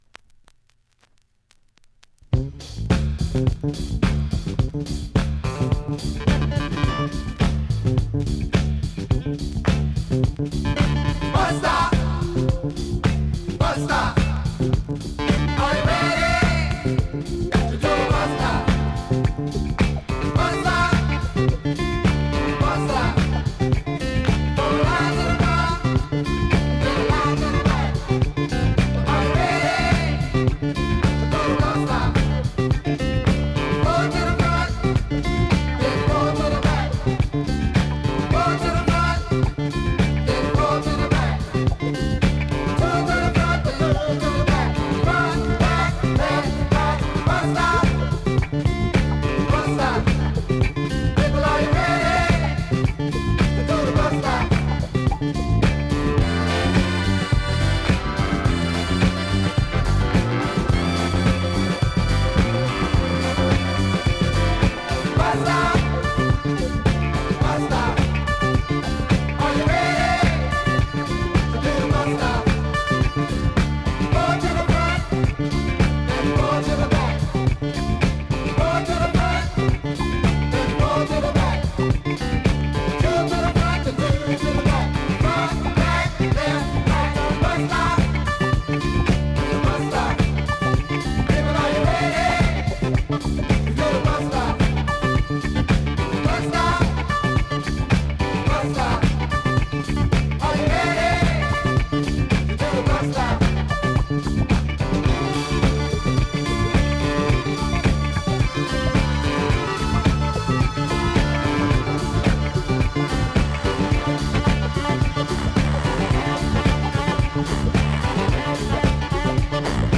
ネタの真っ黒いファンク